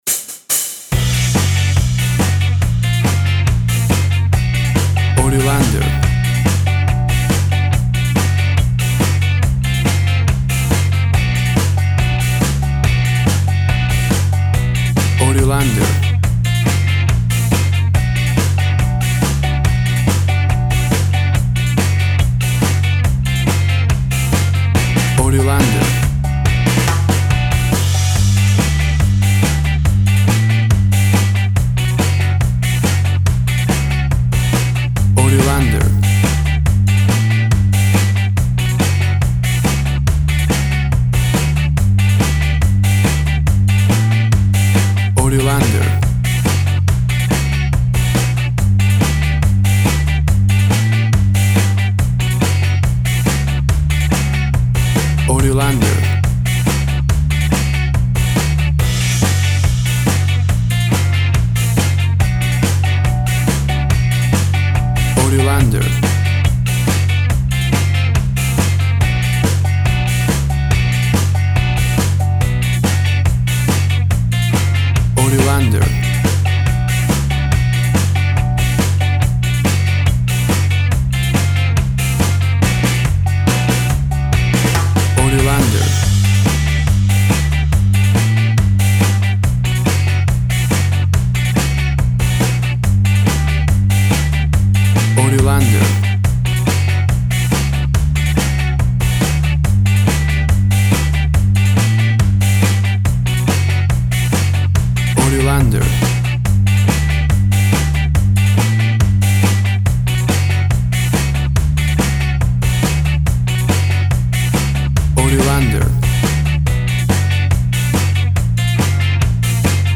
Tempo (BPM) 140